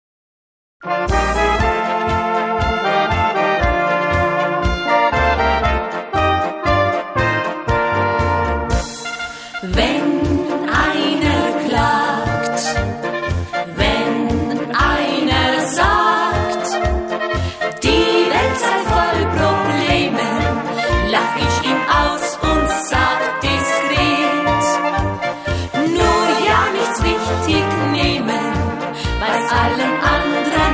traditional Bavarian folk music